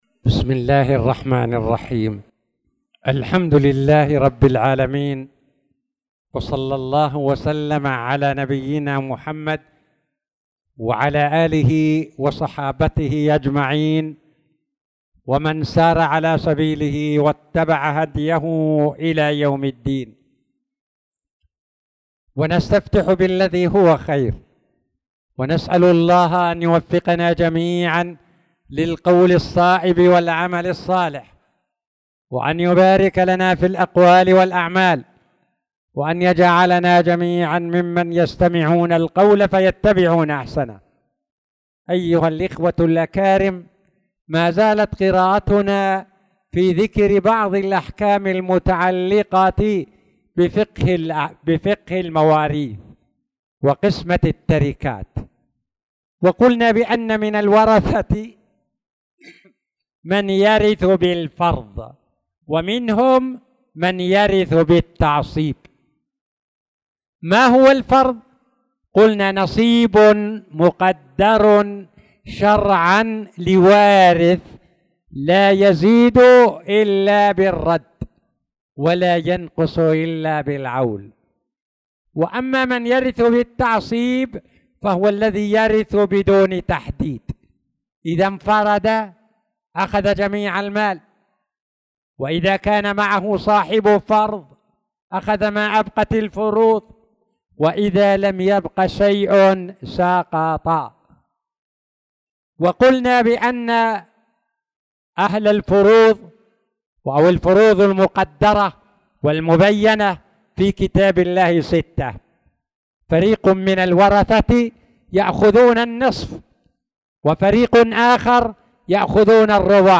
تاريخ النشر ٢٨ شوال ١٤٣٧ هـ المكان: المسجد الحرام الشيخ